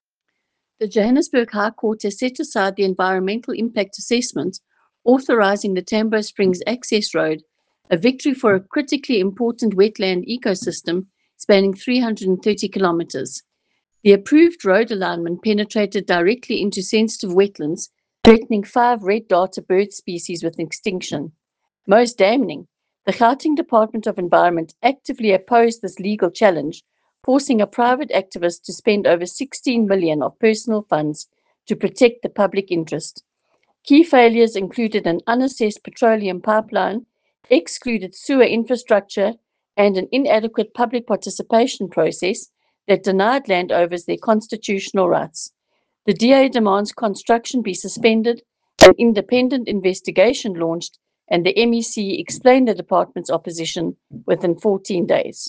Note to Editors: Please find soundbites in
Afrikaans by DA MPL, Leanne De Jager MPL.